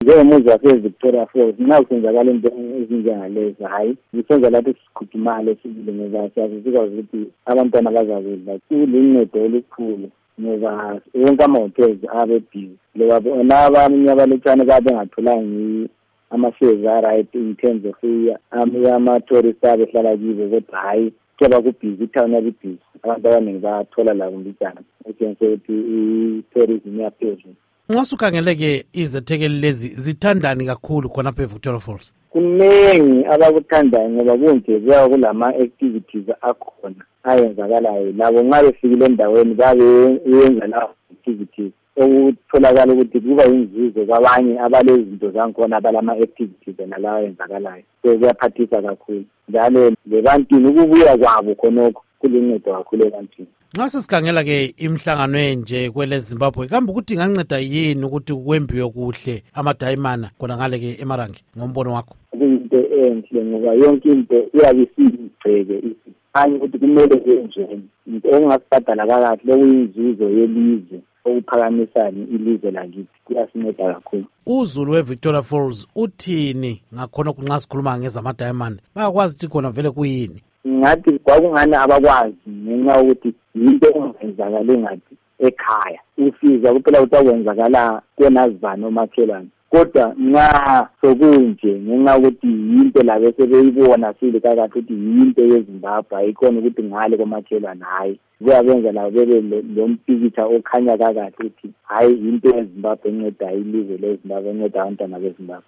Ingxoxo Esiyenze LoMnu.